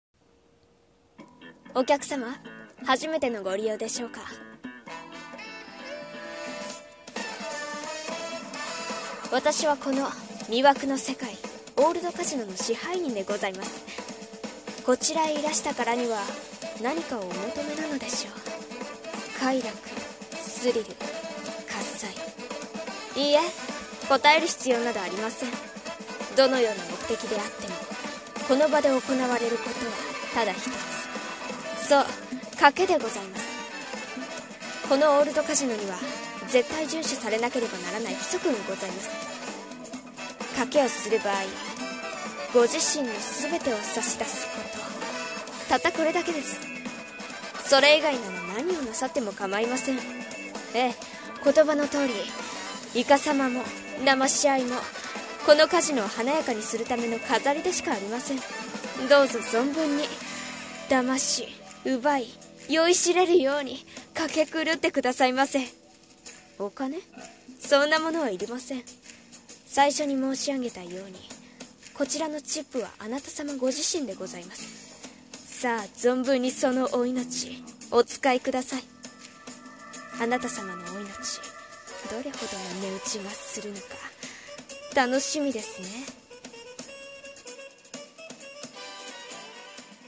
声劇台本 『魅惑のカジノ』